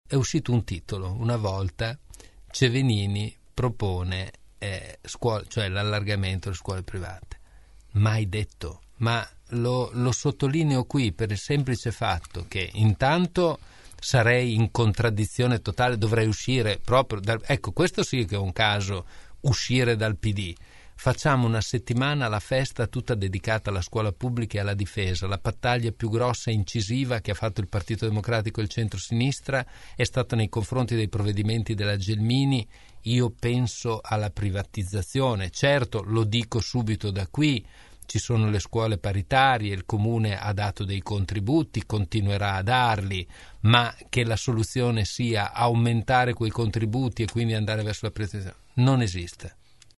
Dalla sala dibattiti centrale della Festa dell’Unità – “la prima volta da protagonista” – come afferma con orgoglio, ai nostri studi per un microfono aperto a caldo.